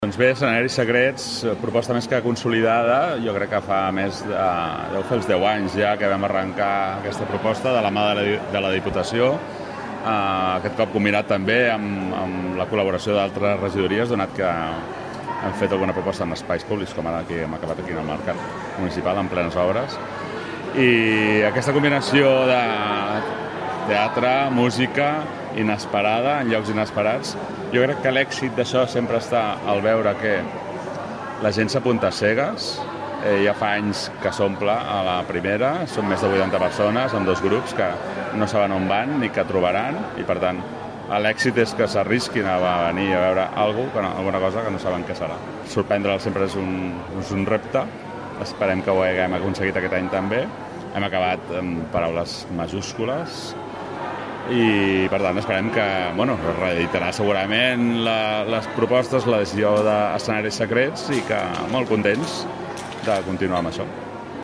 Sergi Corral, regidor de Cultura